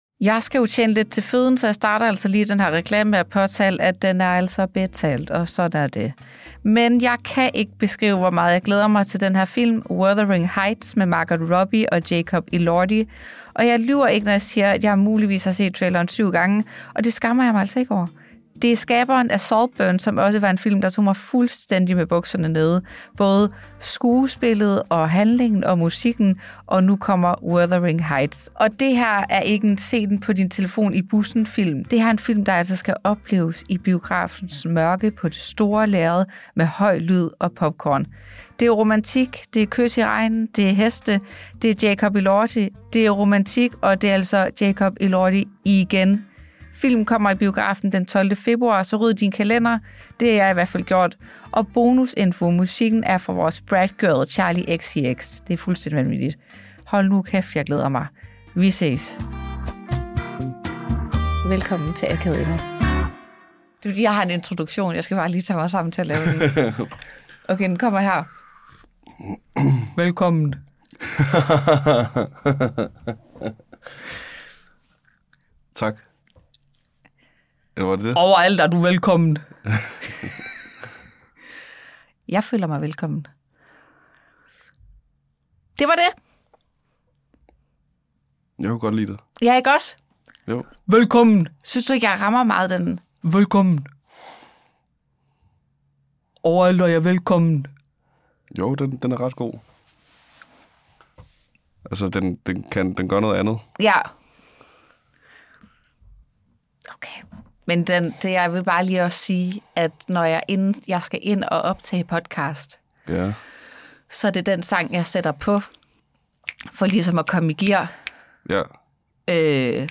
Wow, beklager meget at jeg har tyggegummi i dette afsnit, det vil jeg lige starte med at sige.